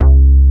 MKS80BSLC2-L.wav